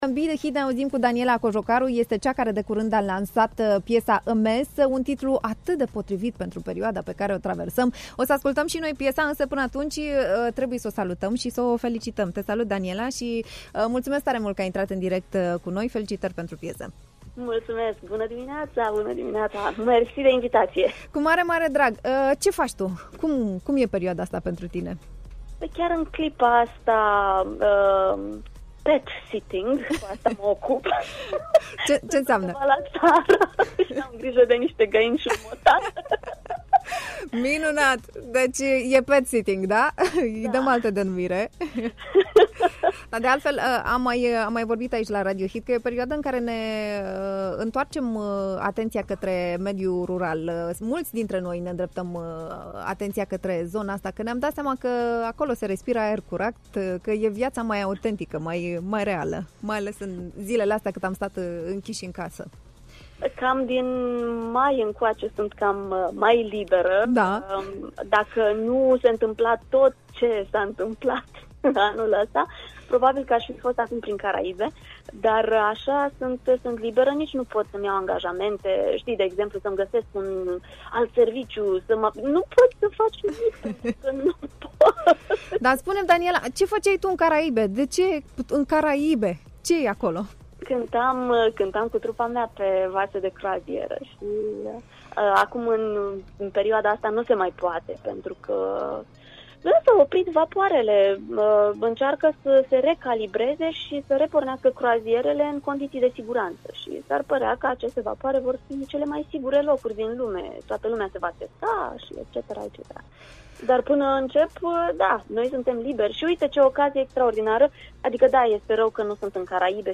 În Be the HIT, am stat de vorbă